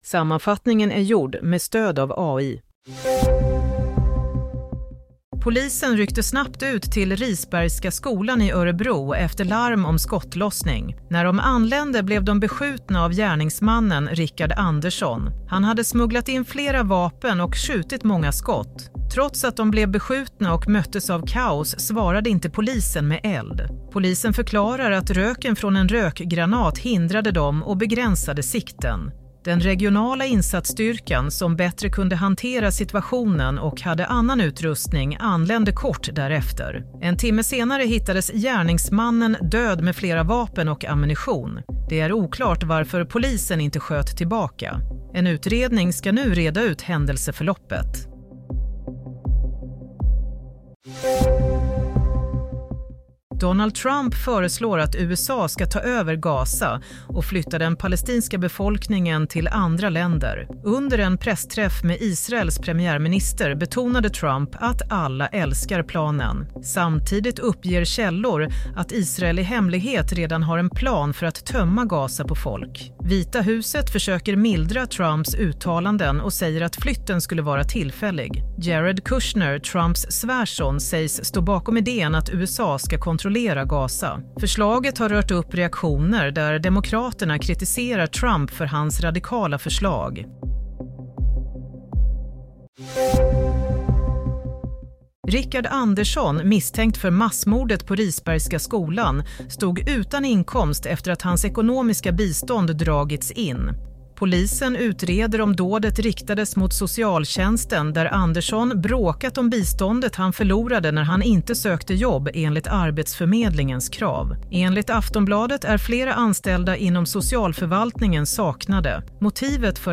Nyhetssammanfattning - 6 februari 16:00